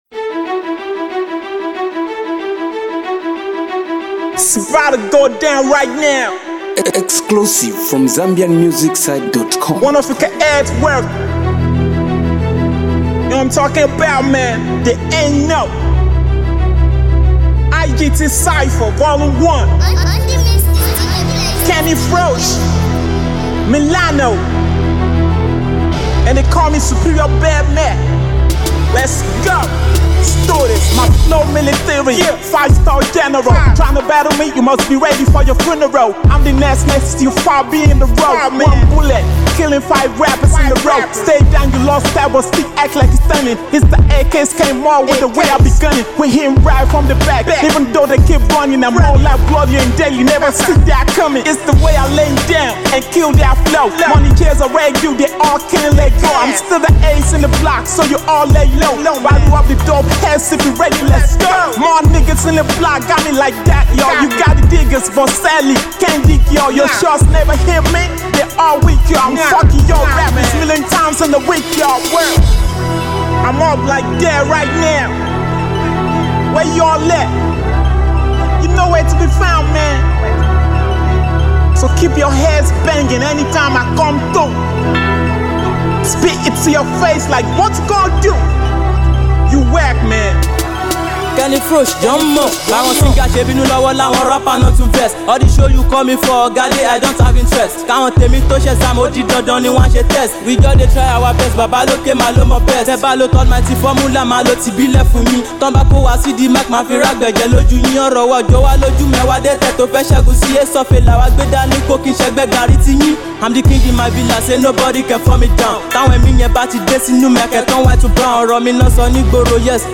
two versatile and dope Rappers